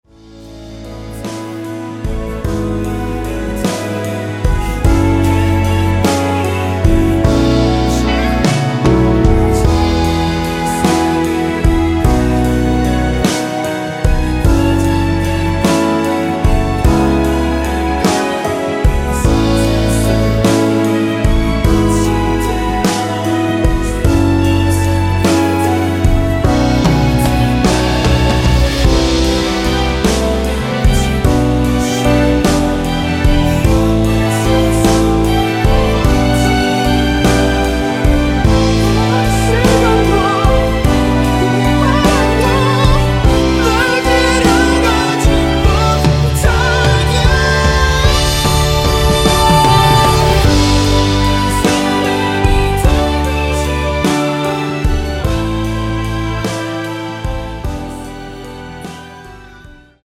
원키에서(-1)내린 멜로디와 코러스 포함된 MR입니다.(미리듣기 확인)
앞부분30초, 뒷부분30초씩 편집해서 올려 드리고 있습니다.
중간에 음이 끈어지고 다시 나오는 이유는